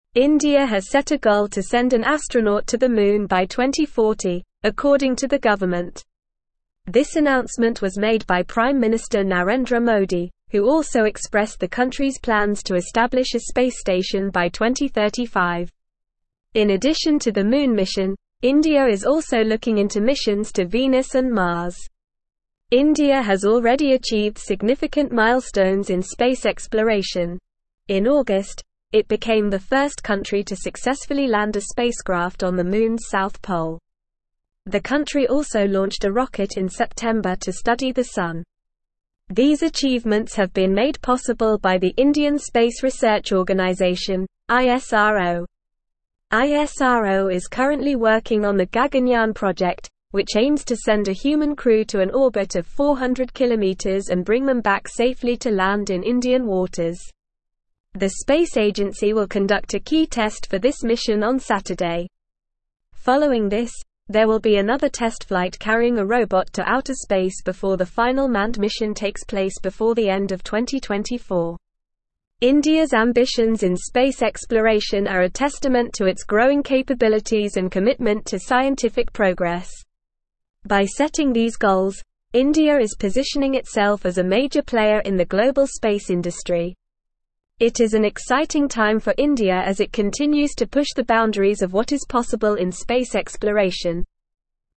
Normal
English-Newsroom-Advanced-NORMAL-Reading-Indias-Ambitious-Space-Goals-Moon-Mars-and-More.mp3